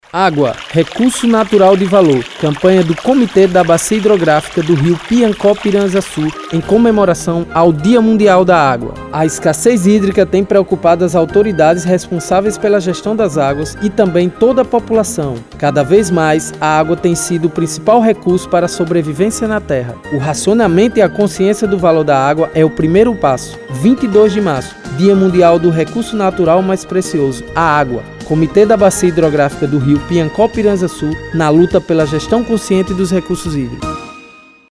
Spot_recursonaturaldevalor.mp3